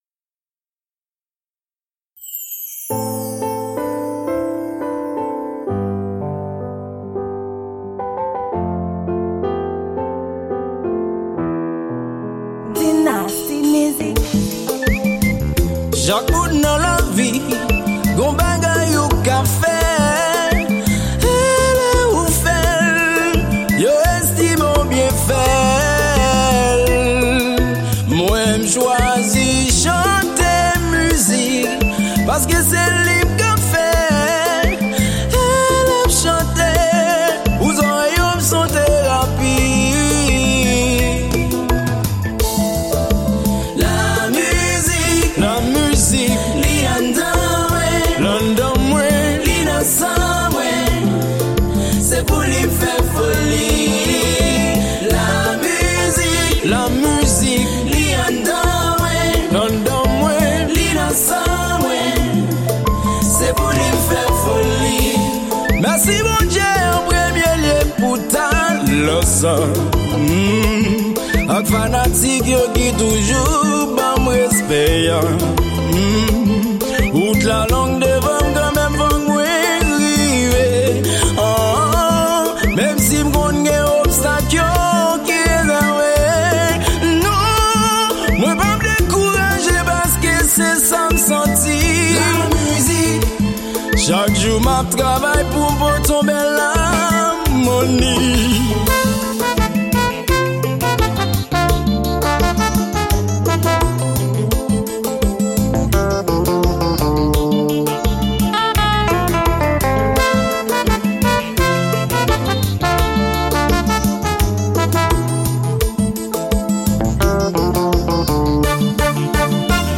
Genre: konpa.